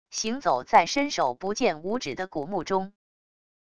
行走在伸手不见五指的古墓中wav音频